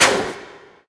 SalemTeleport.ogg